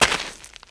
STEPS Gravel, Walk 10.wav